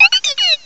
cry_not_dedenne.aif